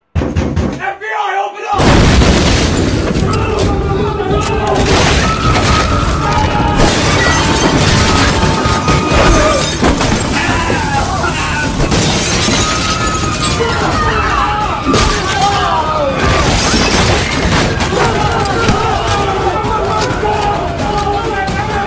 На этой странице собраны звуки, связанные с работой ФБР: перехваты переговоров, сигналы спецоборудования, тревожные гудки и другие эффекты.